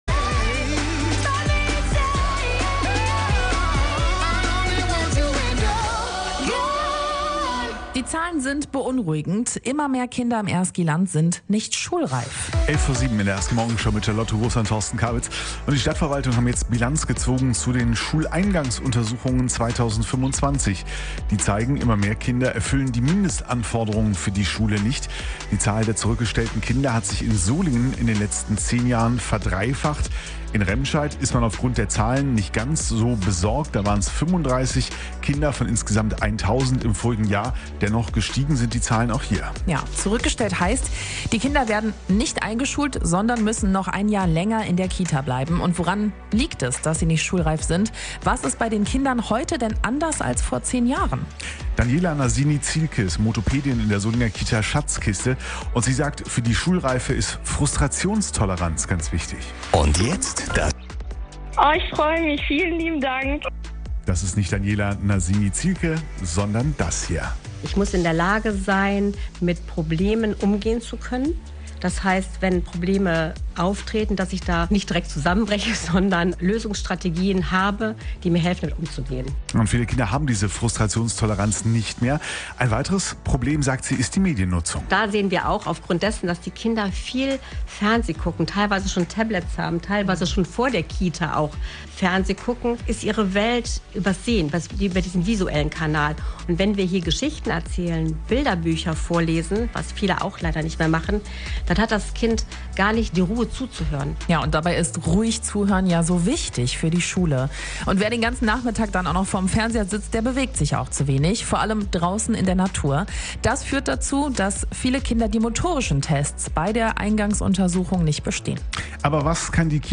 In der Radio-RSG-Morgenshow ging es deshalb nicht nur um die Frage, warum mehr Kinder bei den Untersuchungen auffallen.